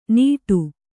♪ nīṭu